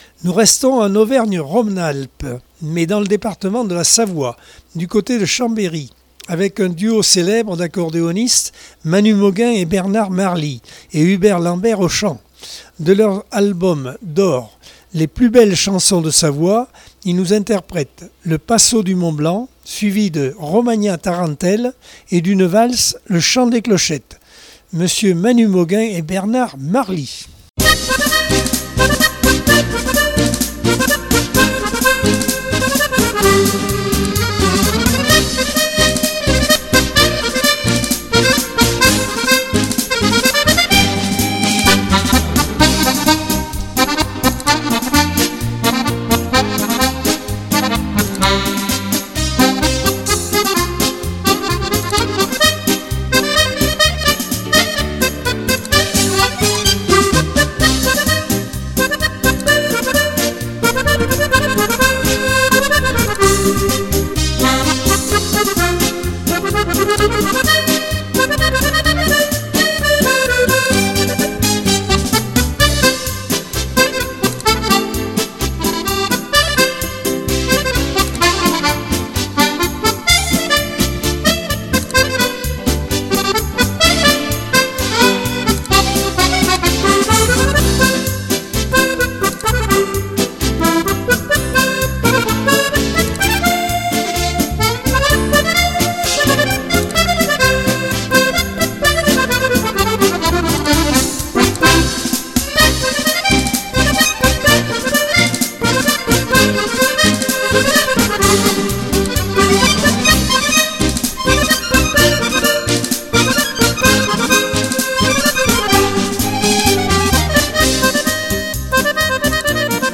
Accordeon 2022 sem 33 bloc 3 - Radio ACX